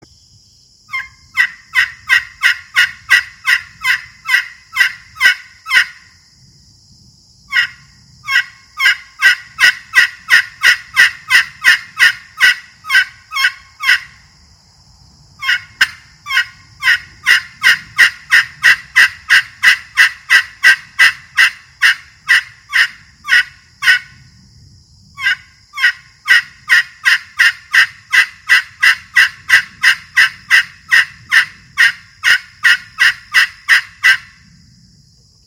Canarywood Slate Pot Call
Includes (1) Two-Piece Striker